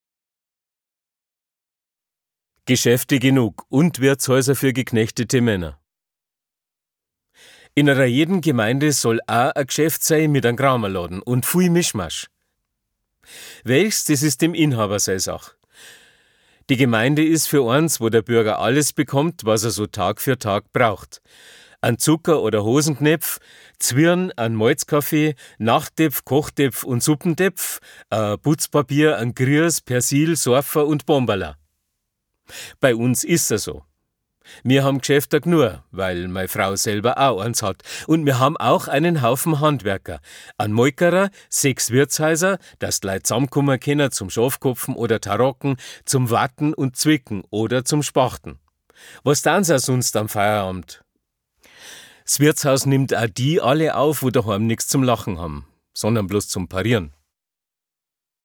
Sprecher